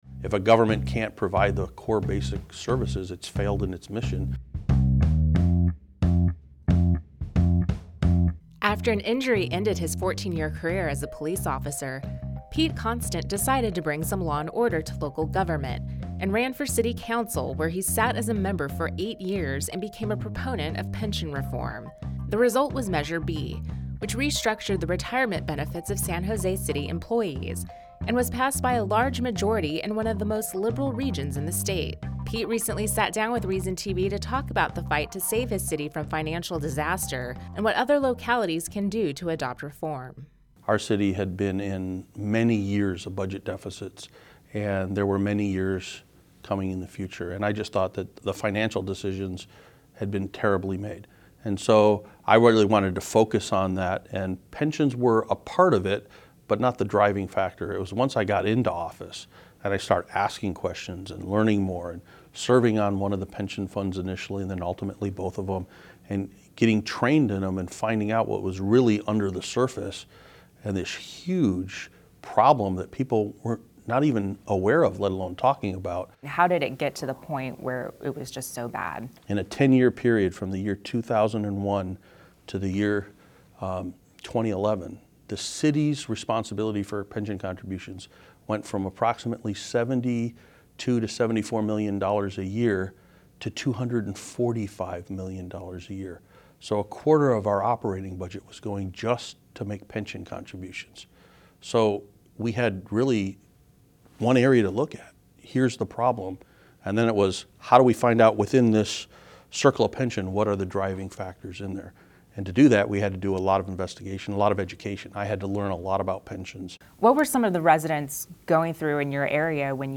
Q&A with Former City Councilman Pete Constant.